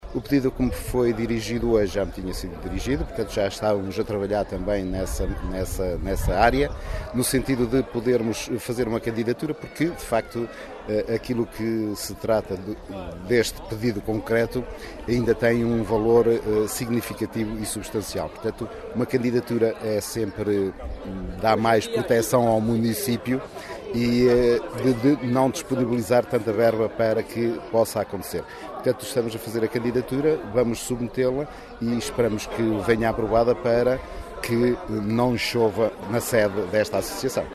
Um pedido dirigido ao município macedense, que o autarca Duarte Moreno, diz estar já em andamento.
Declarações à margem das comemorações do 37º aniversário do Clube de Caça e Pesca de Macedo de Cavaleiros, onde a data foi marcada com uma missa campal, almoço convívio e ainda uma tarde com atividades desportivas.